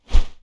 Close Combat Swing Sound 18.wav